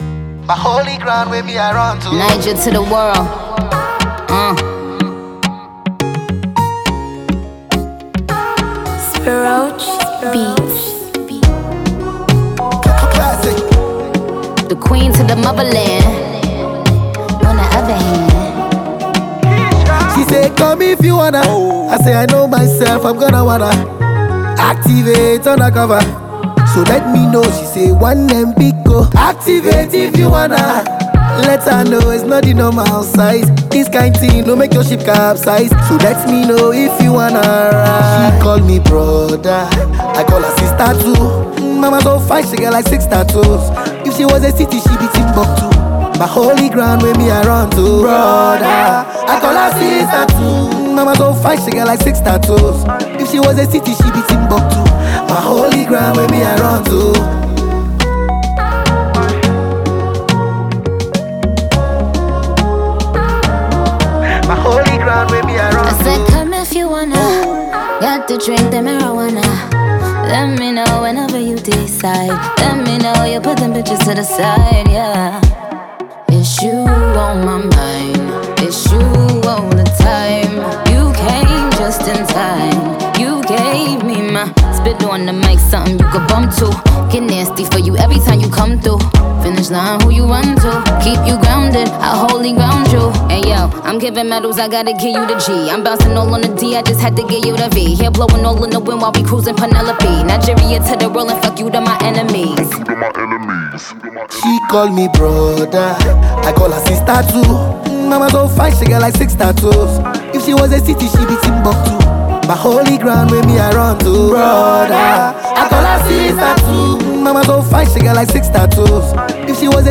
The album mixes Afrobeats with pop and hip-hop sounds.
fun, lively, and full of good vibes